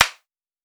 Ball Contact Pad.wav